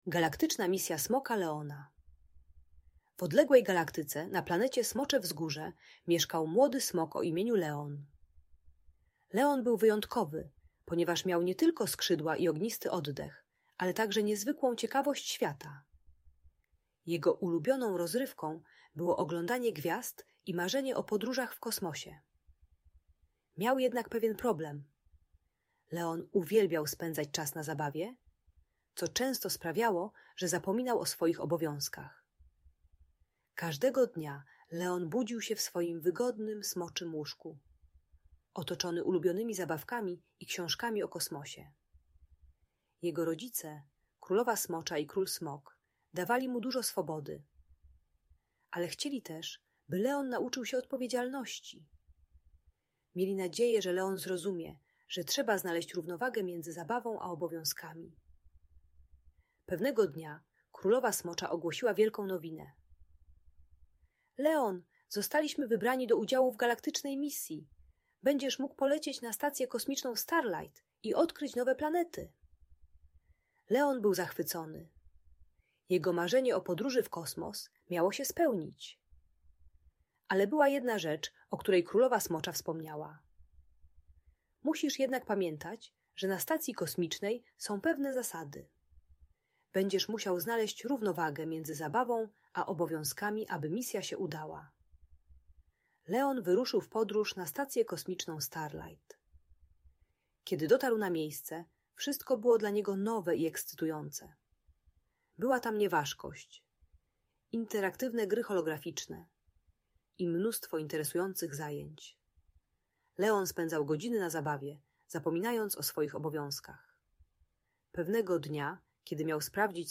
Galaktyczna Misja Smoka Leona - Audiobajka